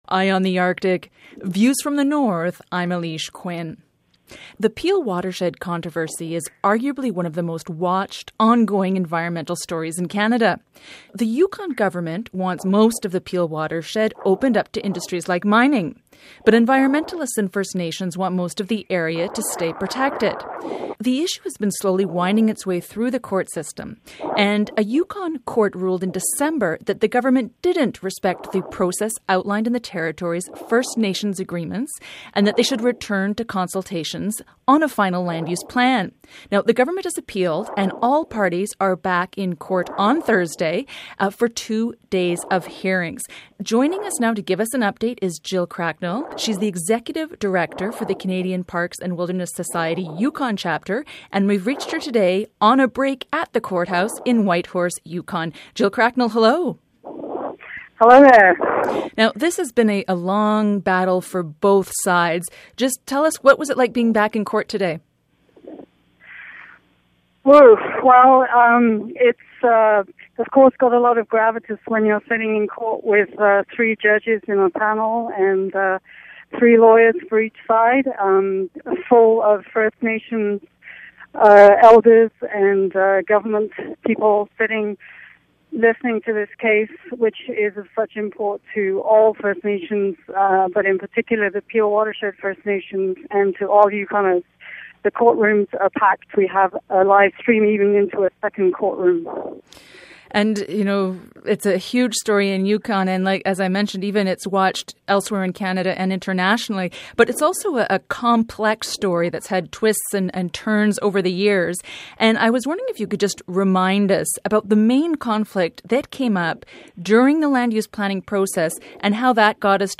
during a court break on Thursday